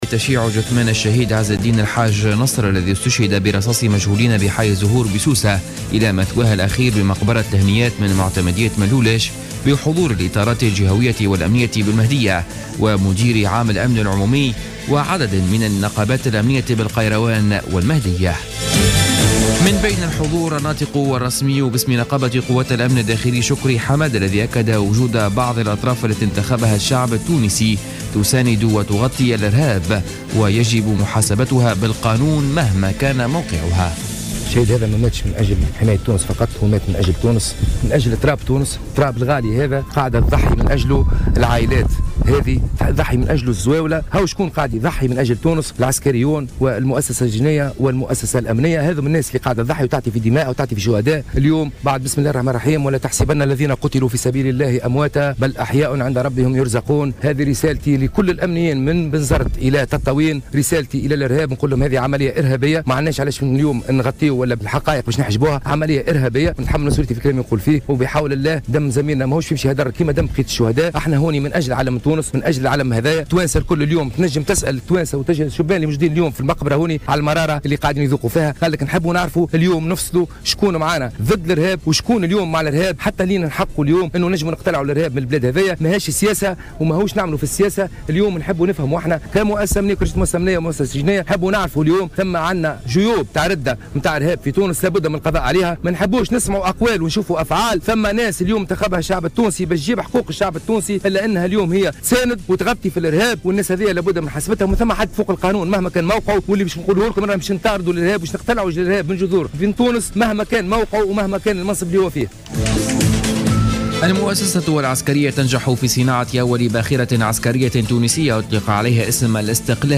نشرة أخبار منتصف الليل ليوم السبت 22 أوت 2015